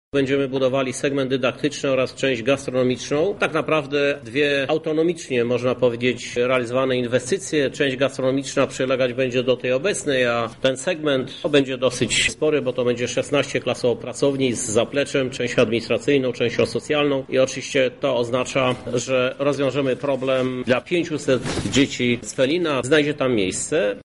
To będą wielomilionowe inwestycje, niezbędne w kontekście rosnącej liczby mieszkańców tych dzielnic – mówi prezydent miasta Krzysztof Żuk: